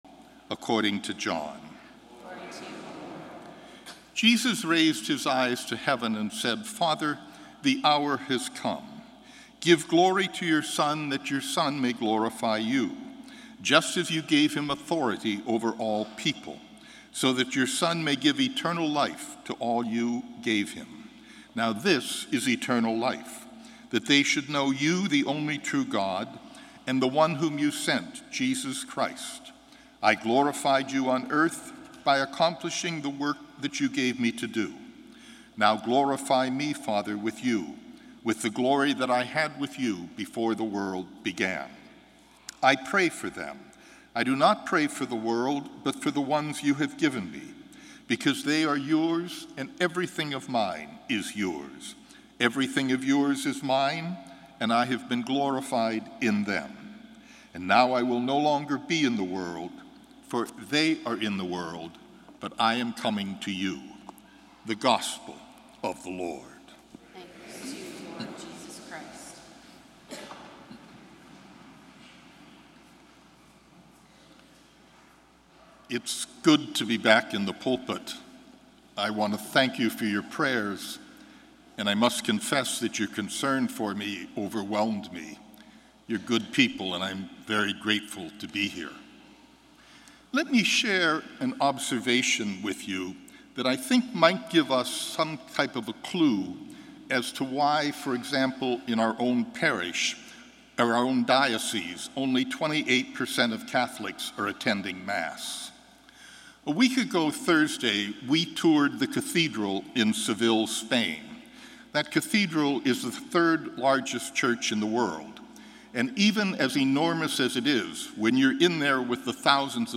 Gospel & Homily May 28, 2017